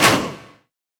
FX (Welcome To The Party).wav